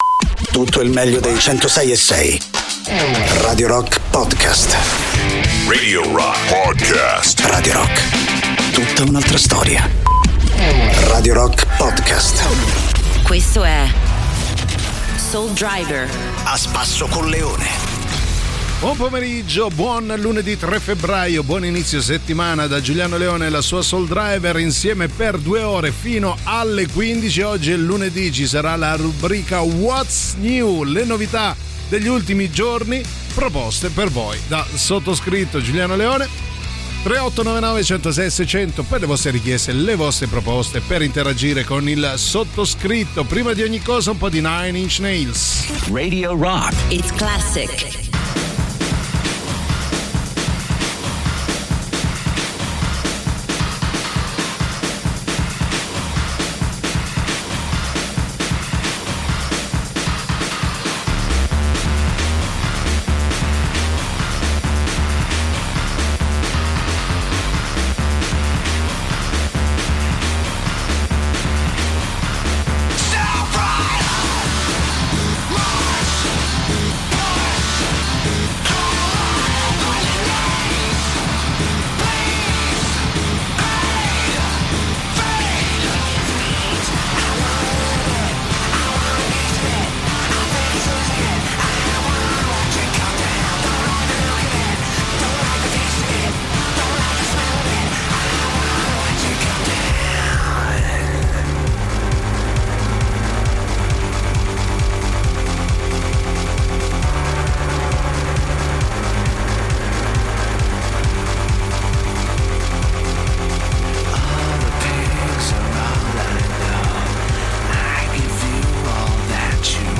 in diretta dal lunedì al venerdì, dalle 13 alle 15, con “Soul Driver” sui 106.6 di Radio Rock.